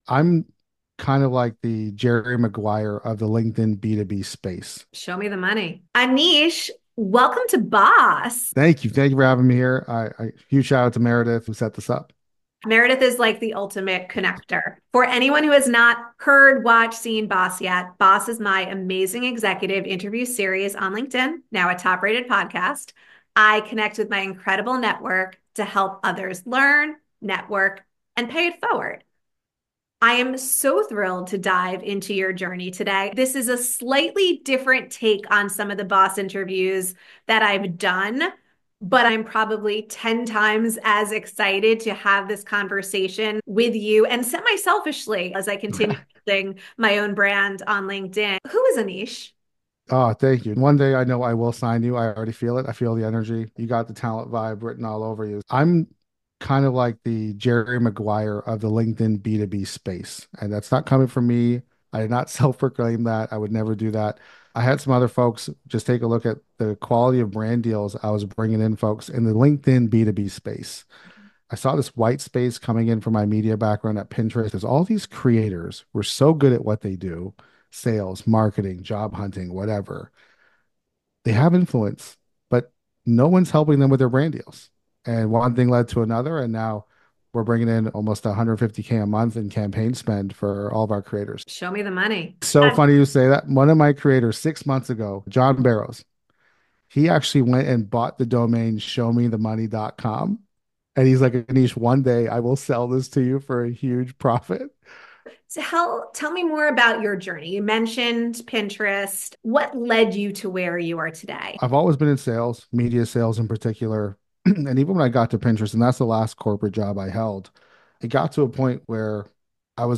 📌 What you’ll learn from this conversation: 1⃣ Why LinkedIn is becoming the go-to platform for influencers and executives.